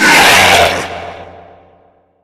sounds / monsters / lurker / hit_5.ogg
hit_5.ogg